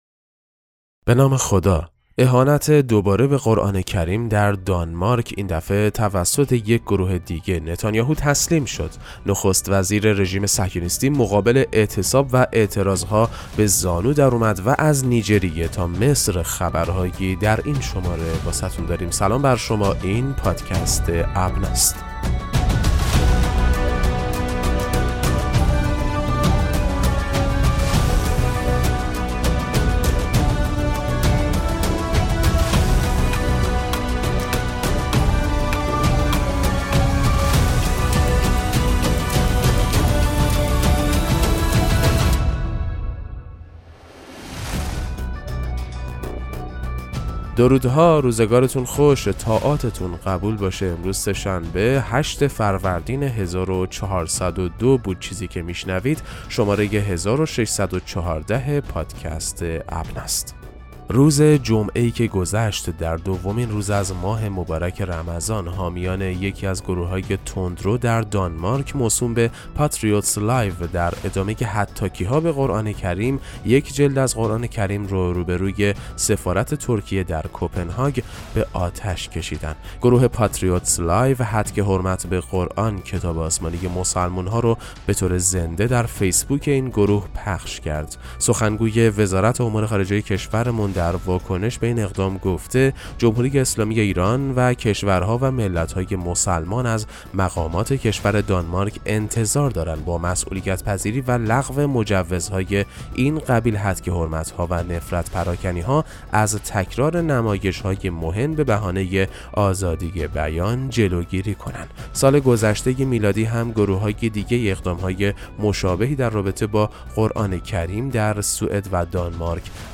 پادکست مهم‌ترین اخبار ابنا فارسی ــ 8 فروردین 1402